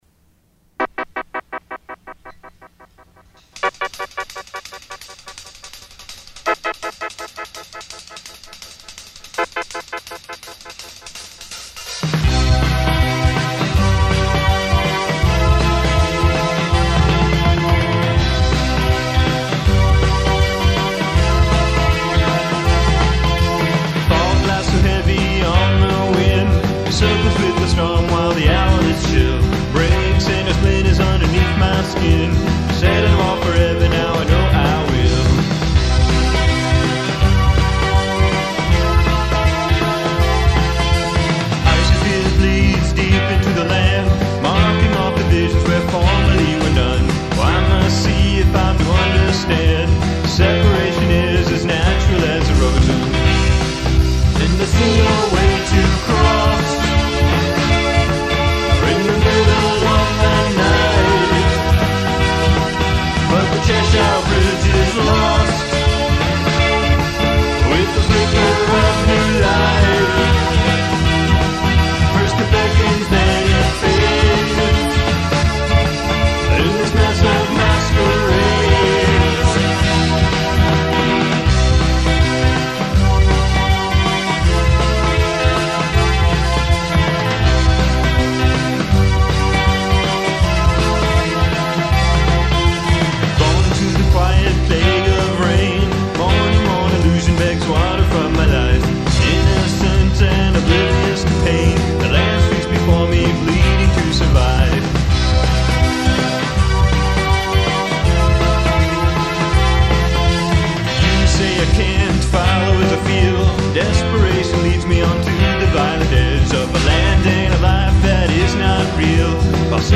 keyboards & vocals
bass & vocals